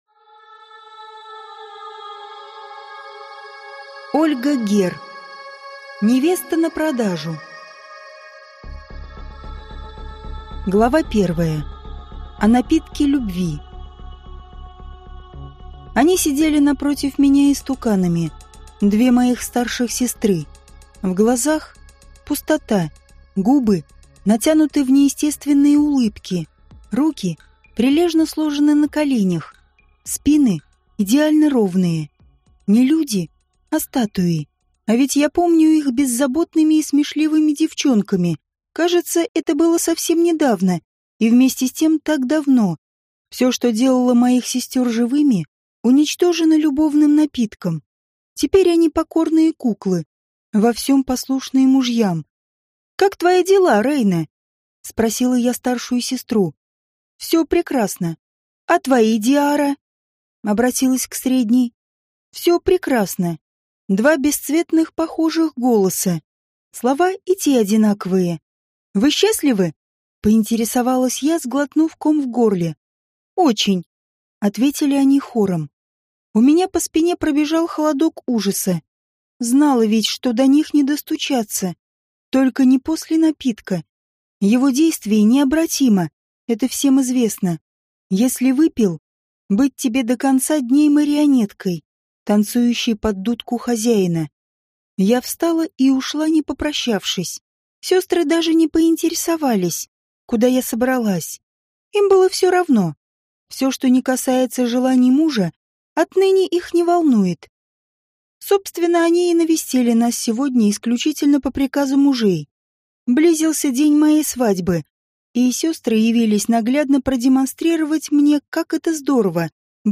Аудиокнига Невеста на продажу | Библиотека аудиокниг